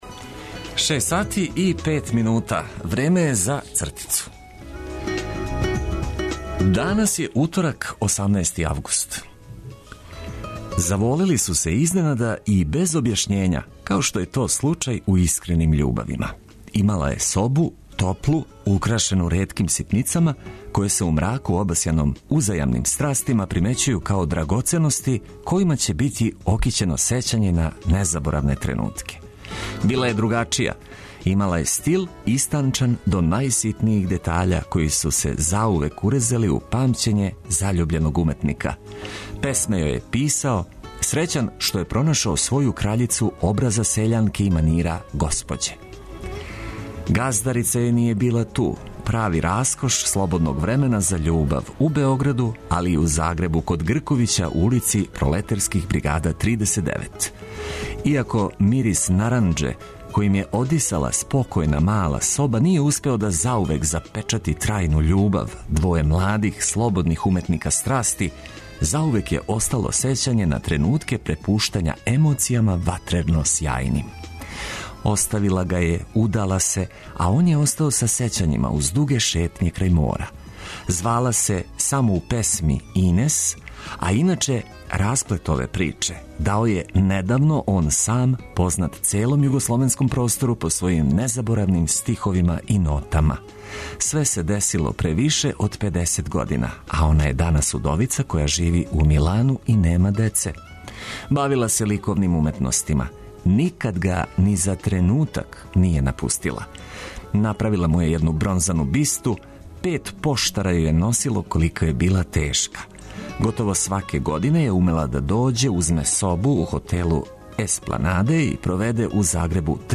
Нека јутро почне уз добру музику коју ћемо прошарати информацијама од којих Вас неће болети глава.